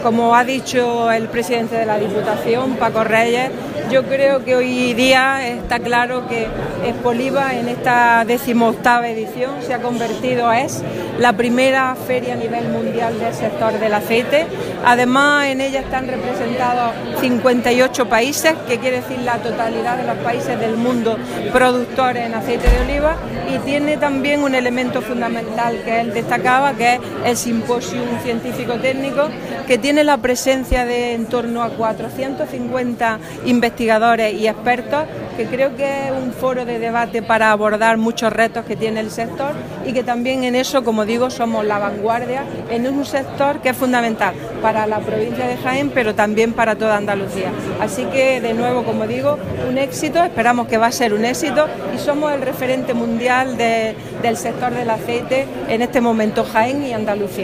Declaraciones de Carmen Ortiz sobre Expoliva 2017